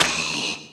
player_zombie_normal_female_footstep9.mp3